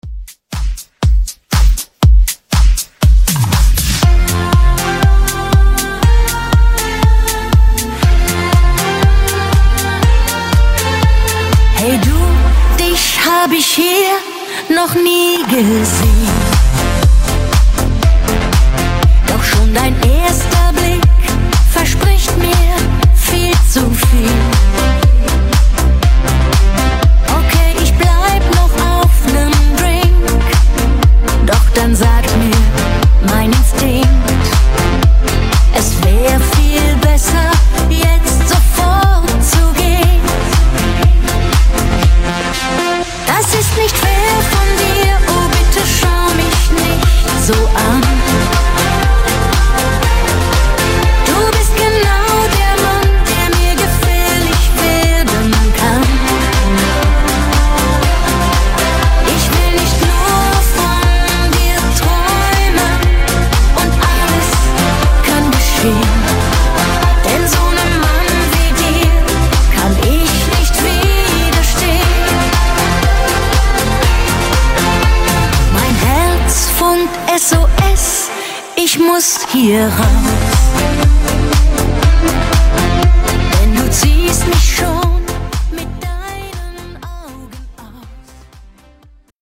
Genres: 90's , AFROBEAT , RE-DRUM
Clean BPM: 132 Time